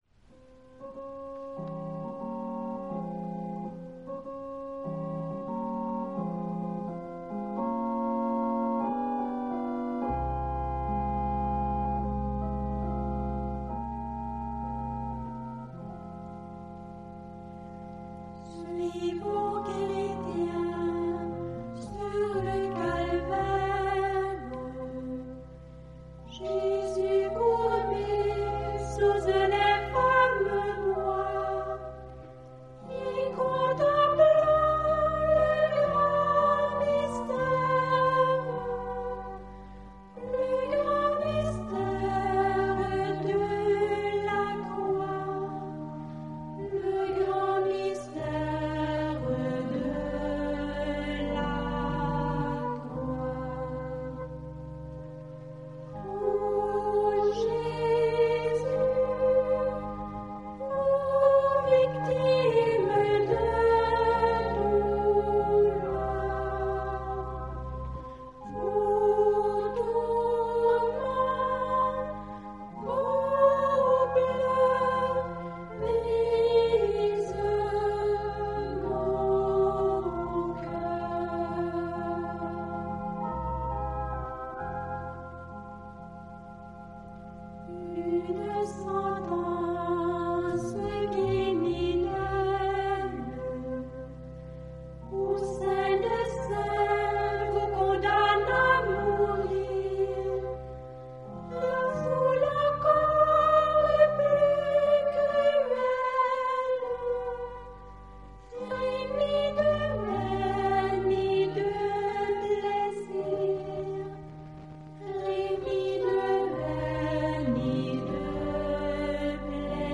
Lecture du Saint Évangile. Cantiques.
Cantique: Suivons chrétiens, sur le Calvaire